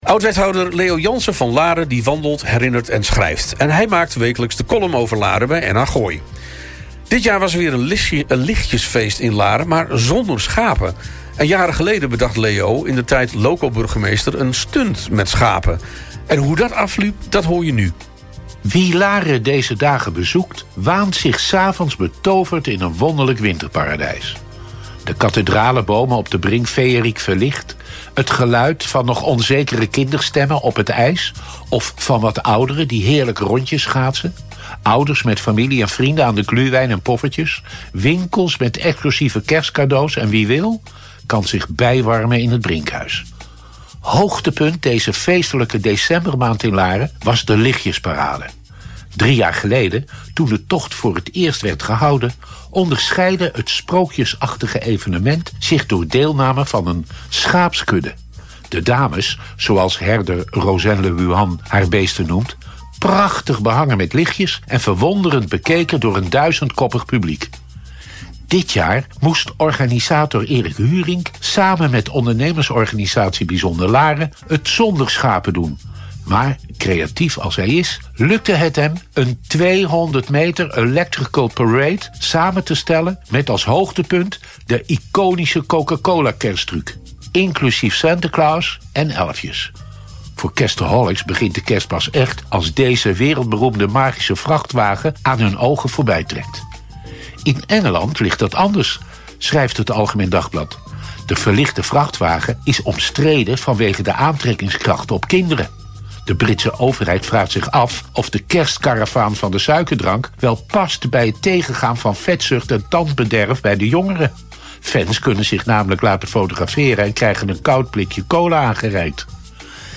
All Inclusive - Column Leo Janssen over het Lichtjesfeest
En maakt wekelijks de column over Laren bij NH Gooi.